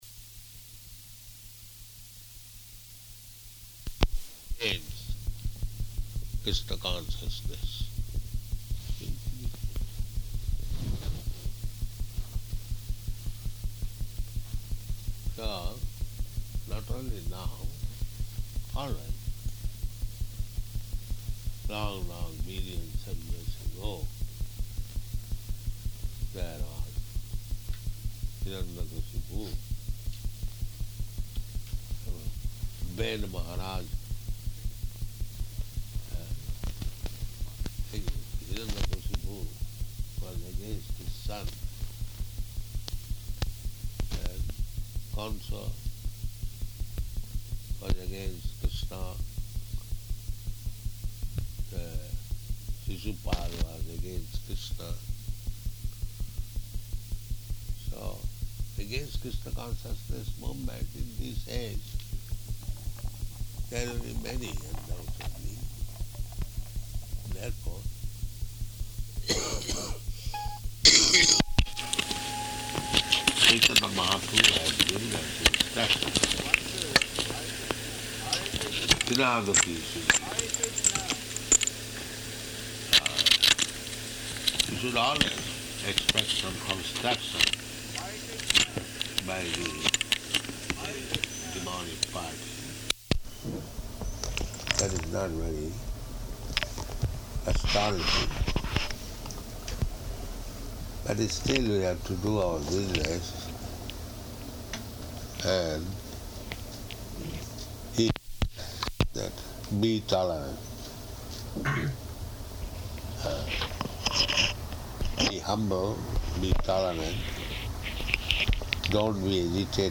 Lecture at 7 Bury Place [partially recorded]